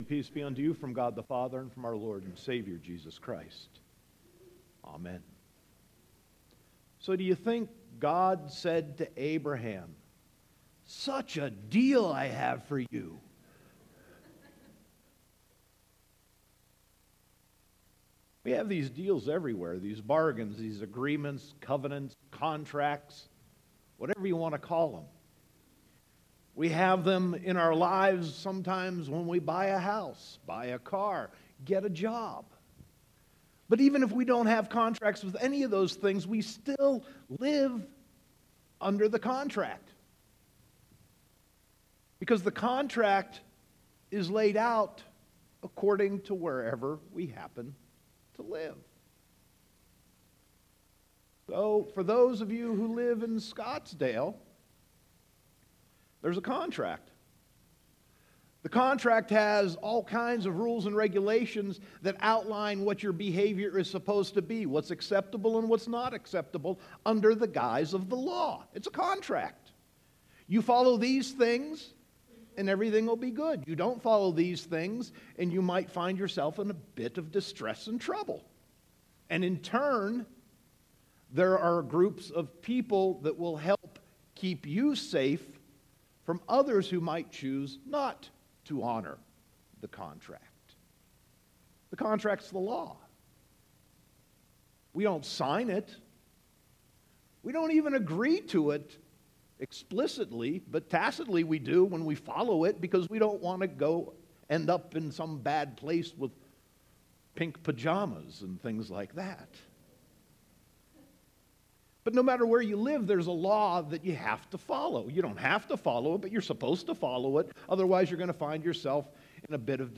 Sermon 2.25.2018